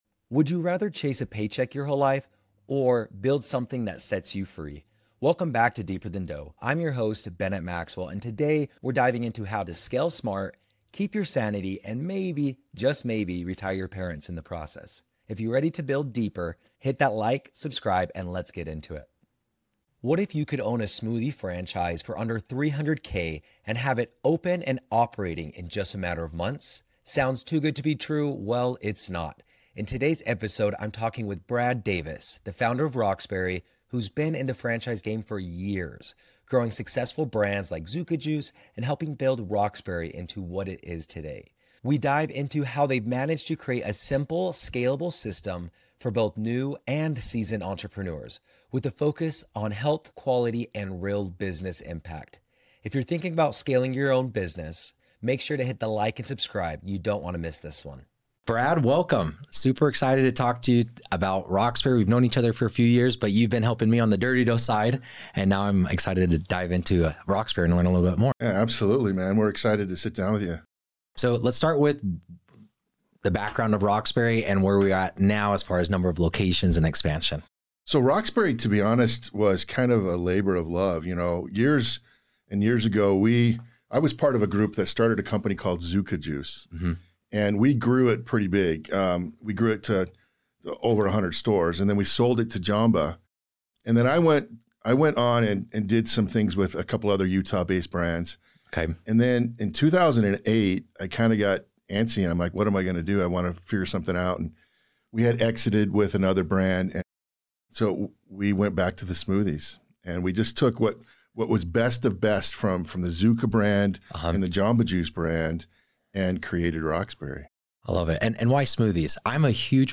The podcast explores themes of self-discovery, fulfillment, and personal growth beyond material success. Through discussions with various guests, the show delves into how real joy and fulfillment can be achieved in both business and personal life.